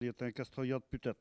Elle provient de Sallertaine.
Locution ( parler, expression, langue,... )